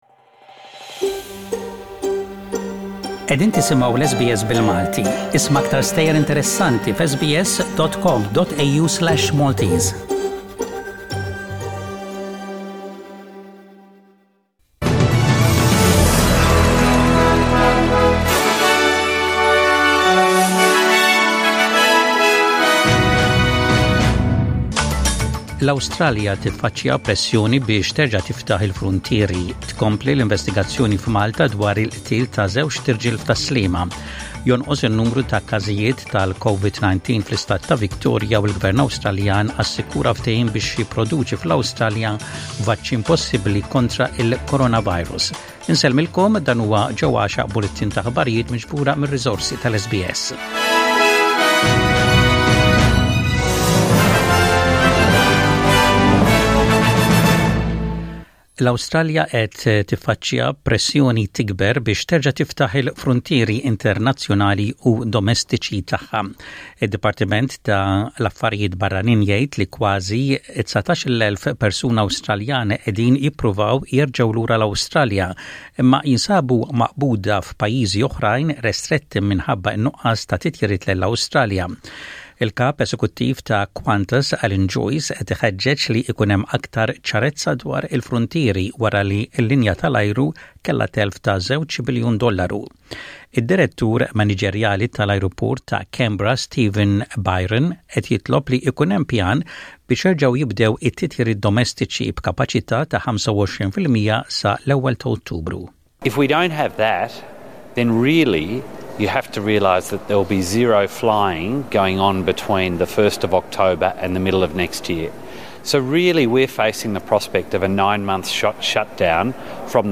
SBS Radio | Maltese News: 21/08/20